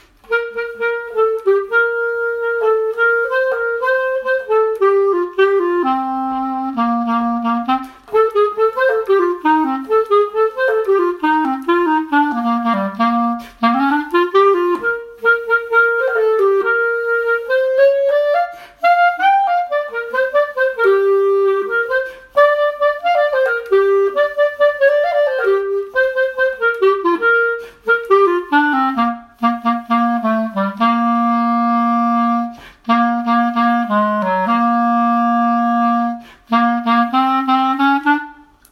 L’ écoute d’ une musique de carnaval
clarinette
clarinette.mp3